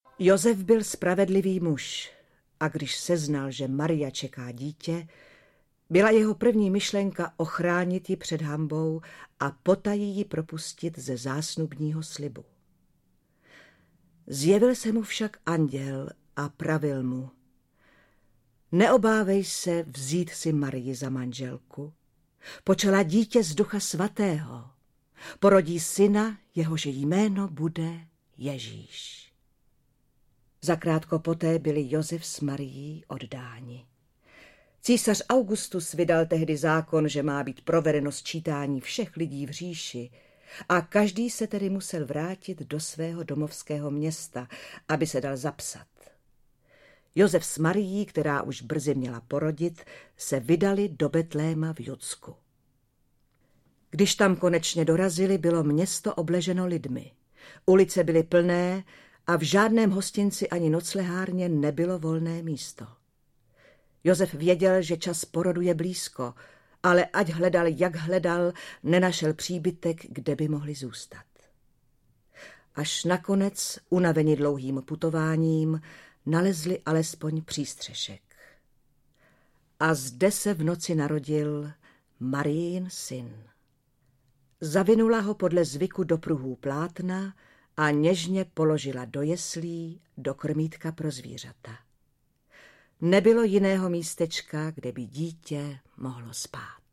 Bible - Nový zákon audiokniha
Ukázka z knihy
Biblické příběhy v populární audio formě, přístupné nejširším posluchačským vrstvám! Znalost Bible patří k všeobecnému vzdělání a tento titul nabízí možnost, jak se v podání populárních hereckých představitelů seznámit v přístupné slovesné formě s knihou knih. 50 Biblických příběhů z "NOVÉHO ZÁKONA" vyprávějí: Marek Eben, Radovan Lukavský, Dana Syslová a Gabriela Filippi.
• InterpretMarek Eben, Dana Syslová, Radovan Lukavský, Gabriela Filippi